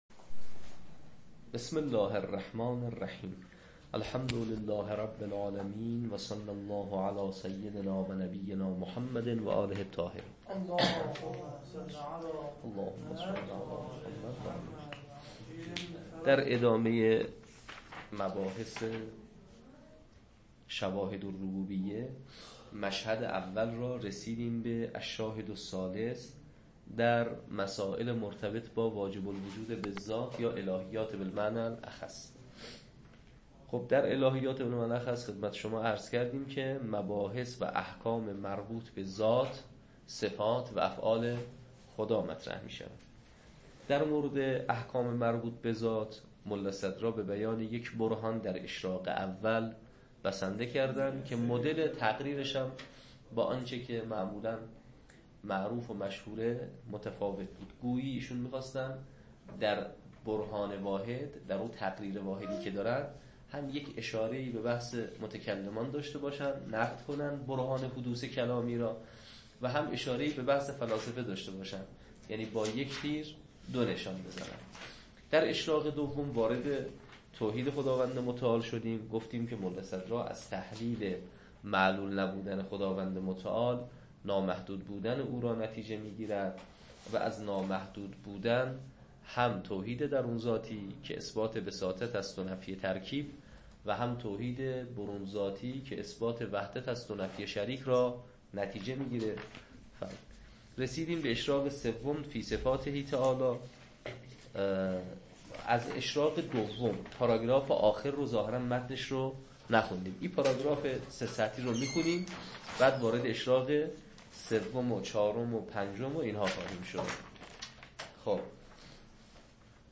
شواهد الربوبیه تدریس